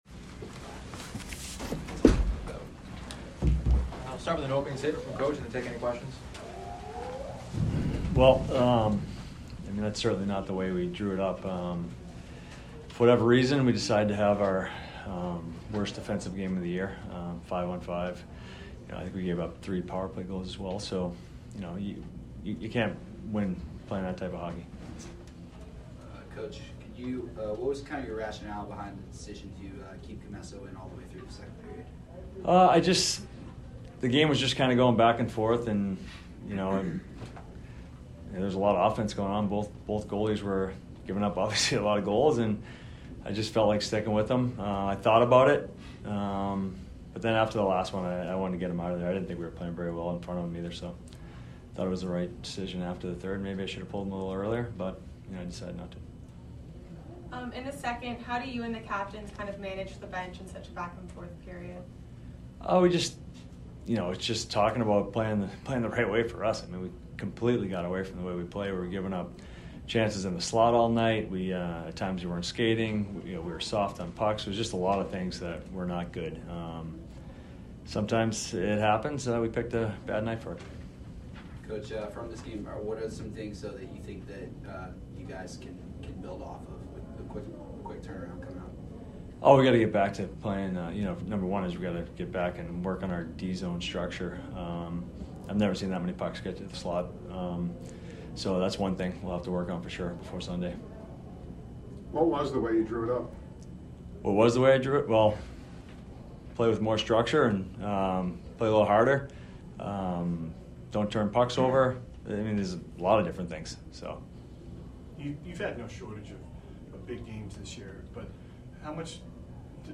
Boston College Postgame Interview